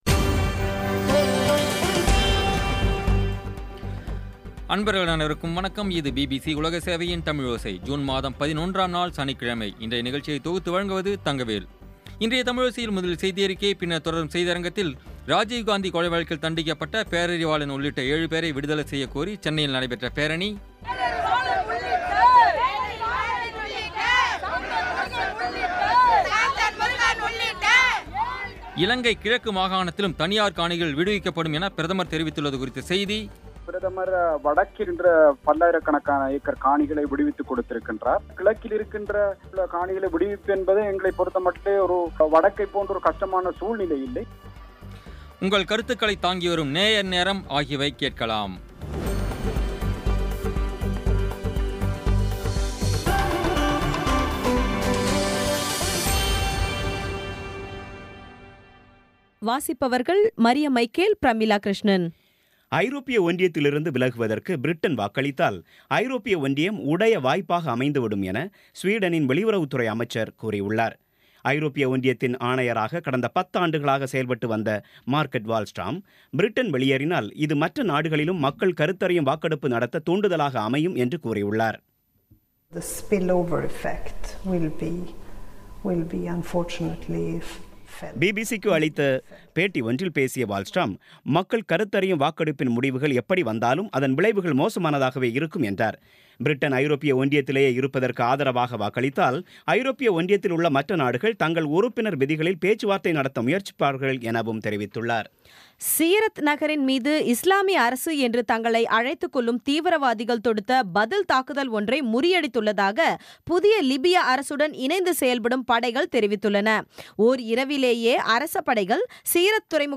இன்றைய பி பி சி தமிழோசை செய்தியறிக்கை (11/06/2016)